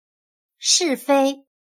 是非/shìfēi/Correcto e incorrecto.